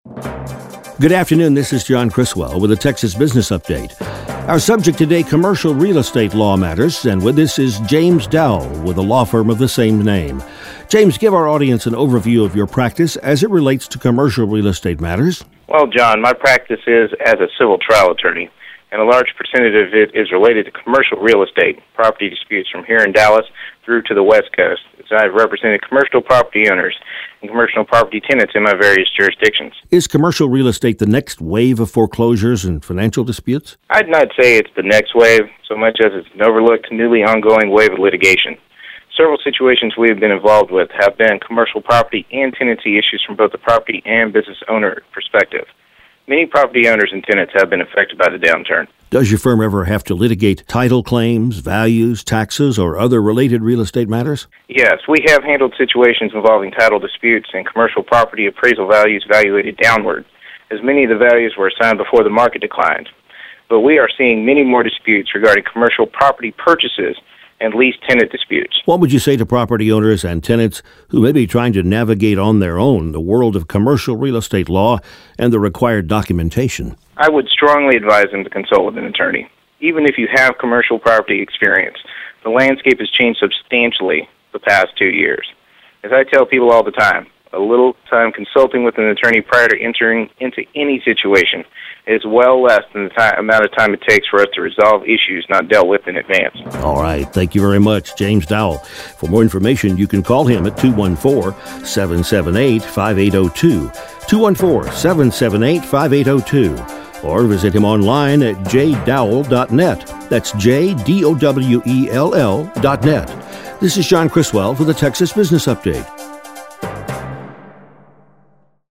Real Property- Texas Business Lawyer Radio Interview Click to Play or follow this link Radio Interview